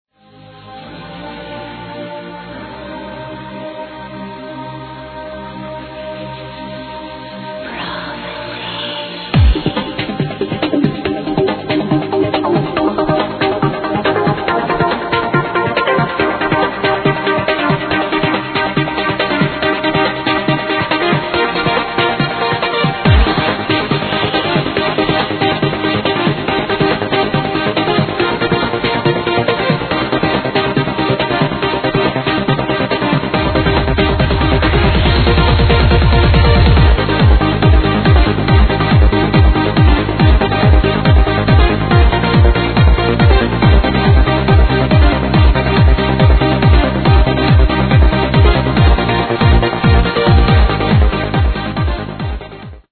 Old trance, Pleas help me to ID